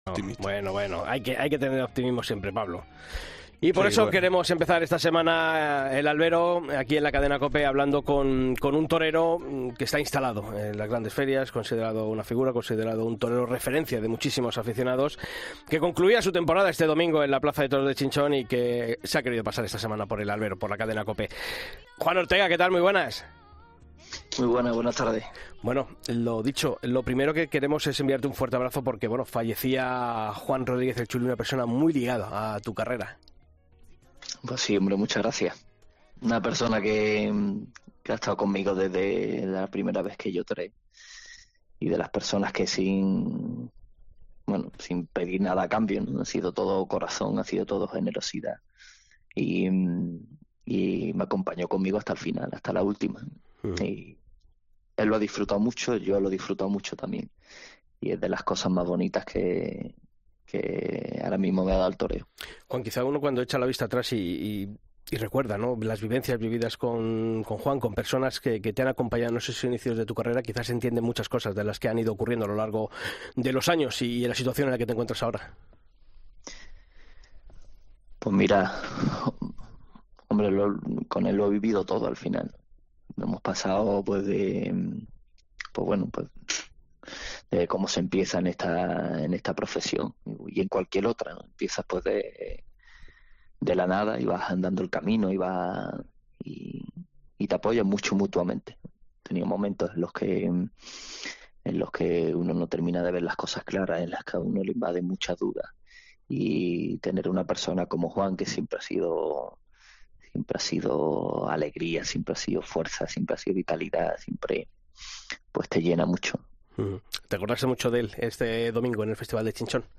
En una conversación pausada, el diestro sevillano profundizó en su concepto, su irregularidad como peaje de su personal tauromaquia y la responsabilidad que siente su generación tras la retirada de una figura como Morante de la Puebla , con quien ha compartido cartel en numerosas ocasiones.